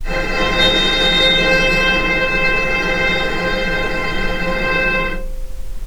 vc_sp-C5-pp.AIF